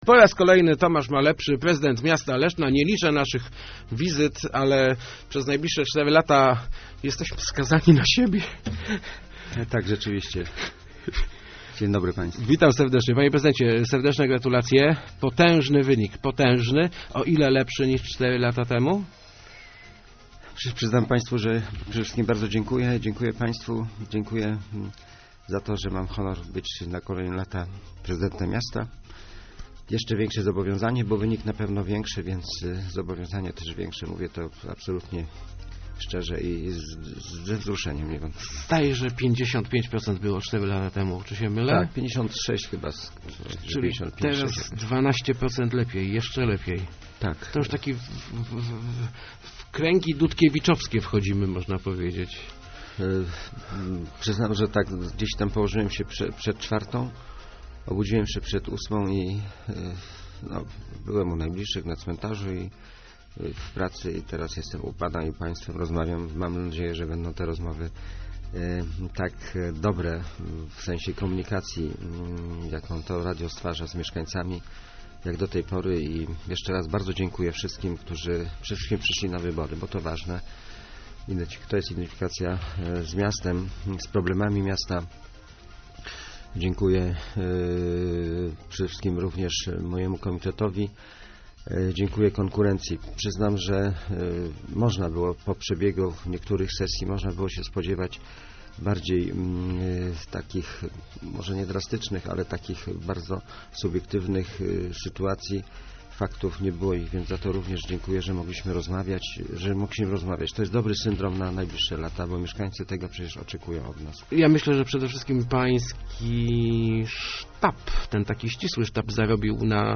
Dziękuję mieszkancom Leszna, dziękuję sztabowi, dziękuję konkurentom - mówił w Rozmowach Elki wyraźnie wzruszony prezydent Leszna Tomasz Malepszy. Zapowiedział też oficjalnie na naszej antenie, że będzie to ostatnia kadencja jego rządów.